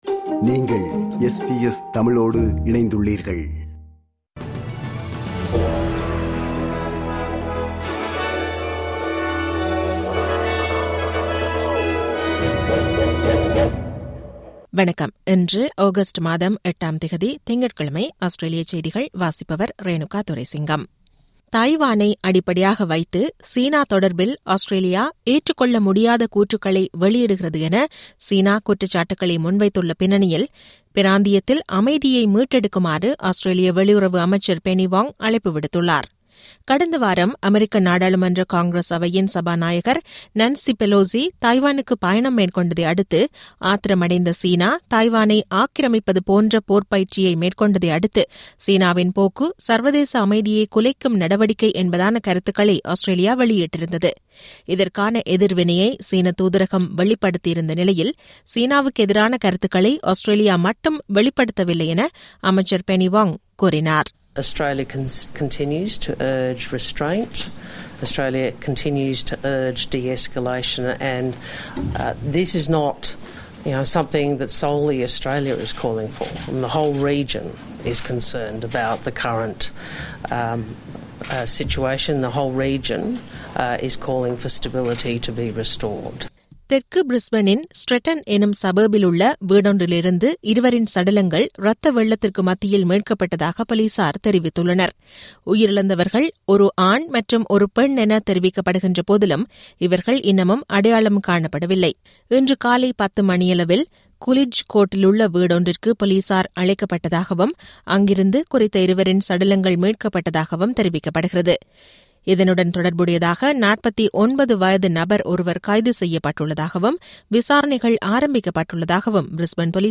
Australian news bulletin for Monday 8 Aug 2022.